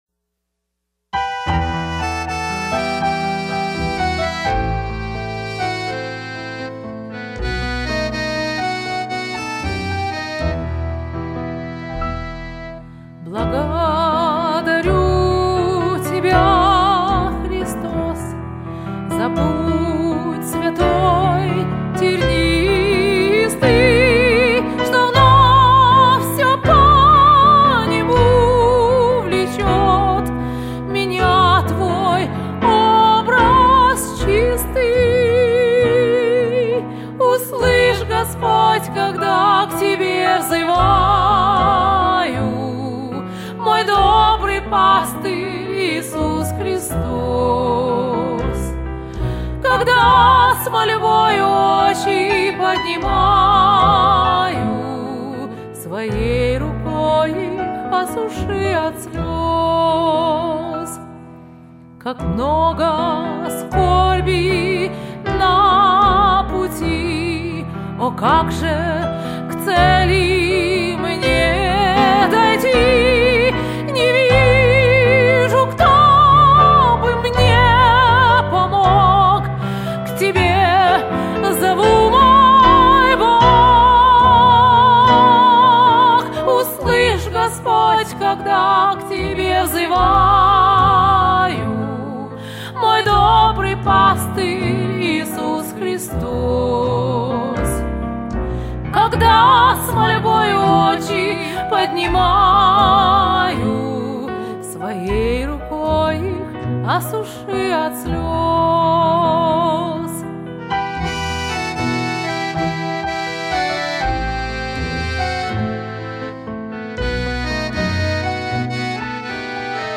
Для сольного (дуэта) исполнения